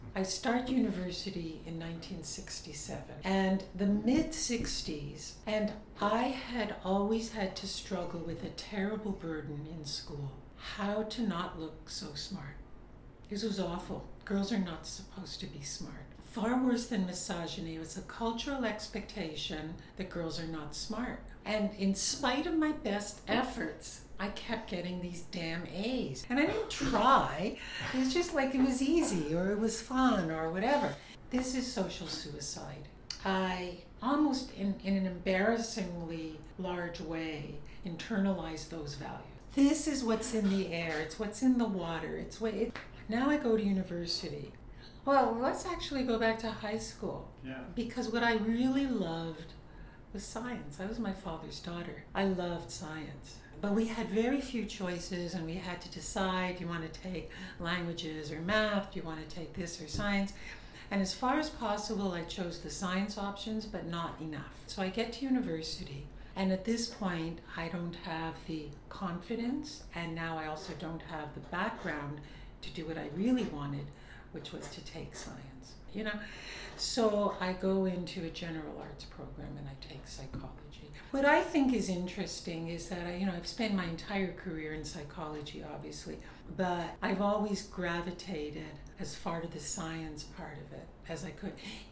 In meeting with me she prefaced the conversation (and the recording I took) with the following consideration: her vocal tract was degraded by a health issue, and is acoustically different than what she grew up with.